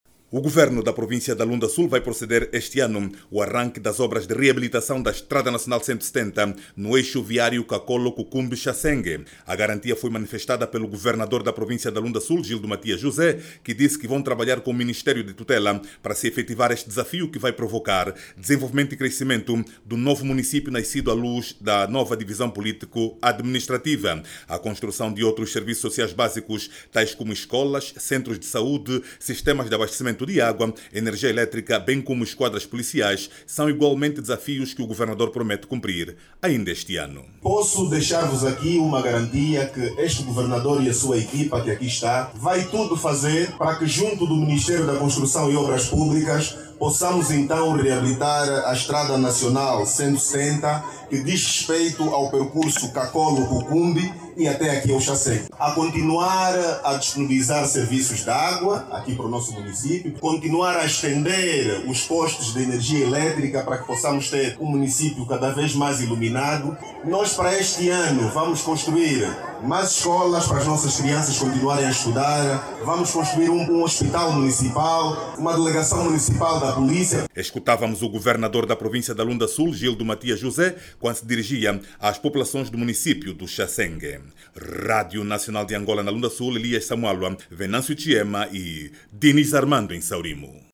Gildo Matias José, anunciou também a construção de infraestruturas nos municípios recém-criados no âmbito da nova divisão político-administrativa. Ouça o desenvolvimento desta matéria na voz do jornalista